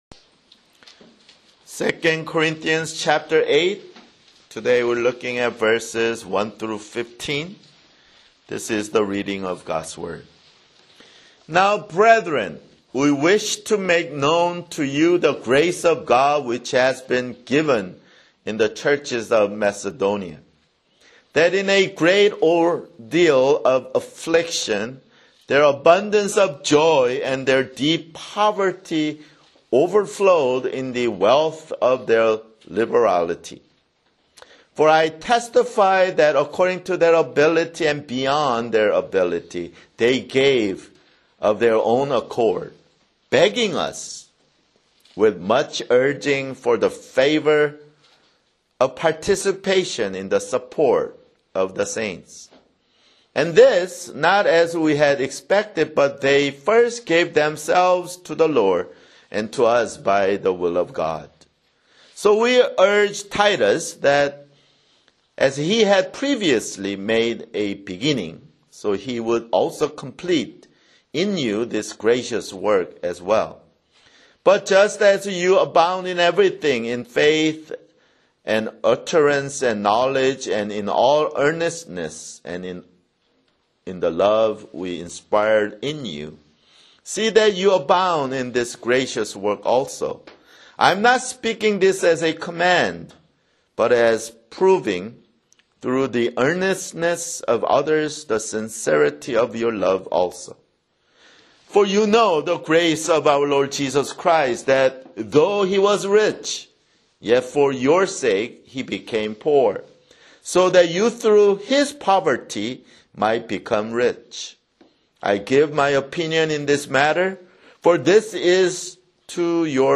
[Sermon] 2 Corinthians (42)